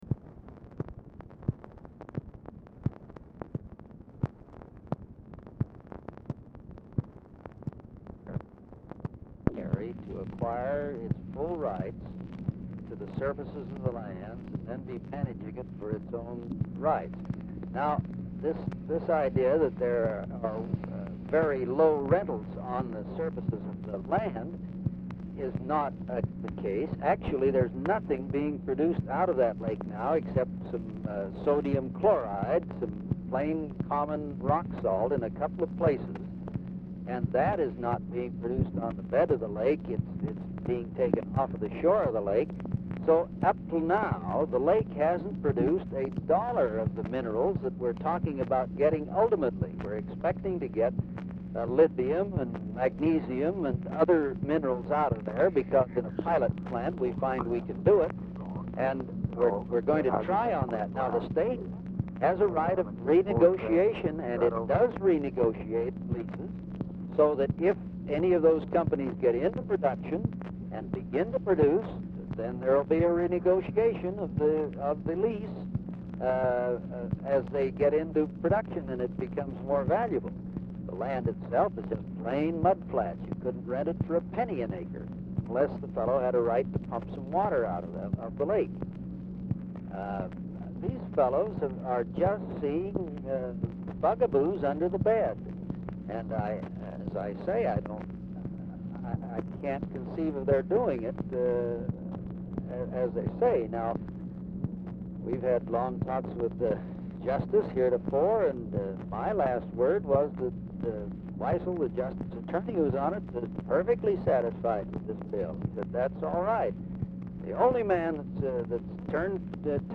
Telephone conversation # 10209, sound recording, LBJ and FRANK "TED" MOSS, 6/3/1966, 1:04PM
LBJ SPEAKS TO SOMEONE IN HIS OFFICE DURING CALL
Format Dictation belt
Location Of Speaker 1 Oval Office or unknown location